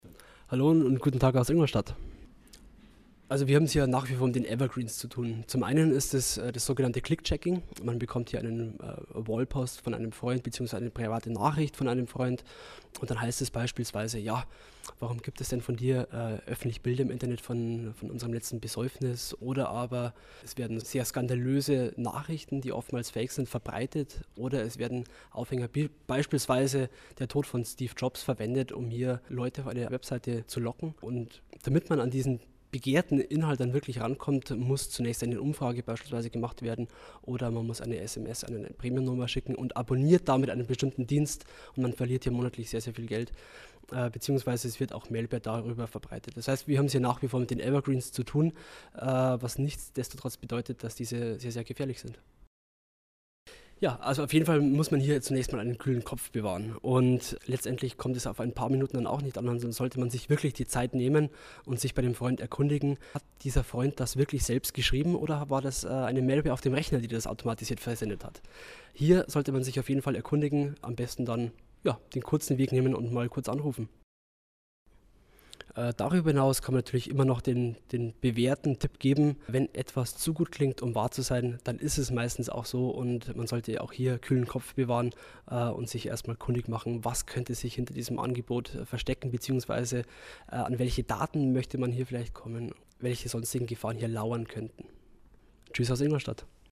Kollegengespräch: Trends bei sozialen Netzwerken
O-Töne / Radiobeiträge, , , , ,